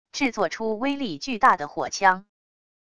制作出威力巨大的火枪wav音频